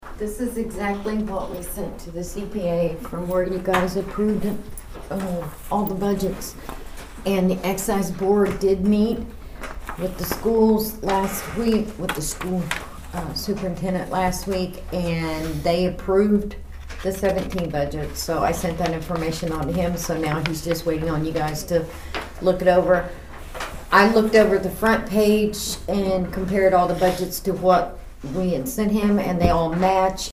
The Nowata County Commisioners held their weekly meeting on Tuesday.
Nowata County Clerk Kay Spurgeon gave an explanation to the board.